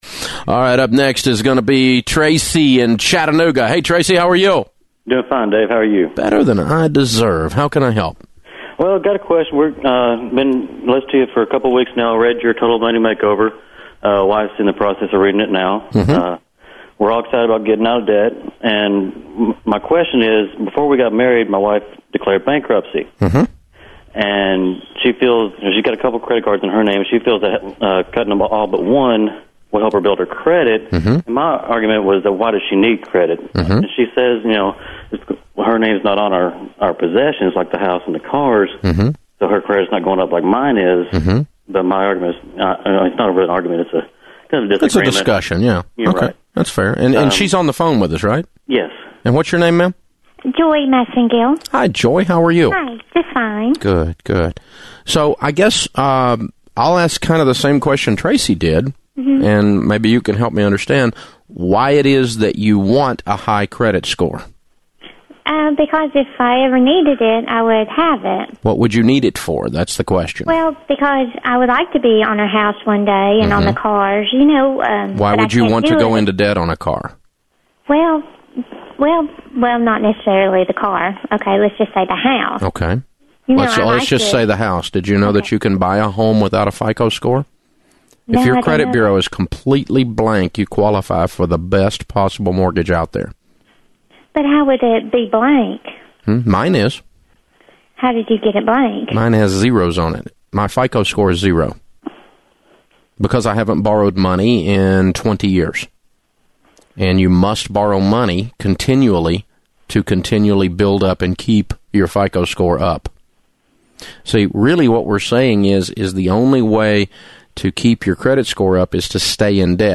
In fact, we asked, and Dave Ramsey gave us permission to “re-podcast” one of the more entertaining phone calls from his radio show. The call and his commentary on it lasts about 10 minutes but it is worth the time spent.